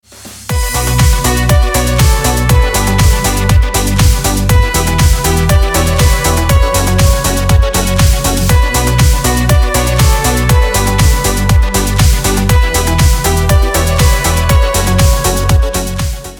• Категория: Рингтоны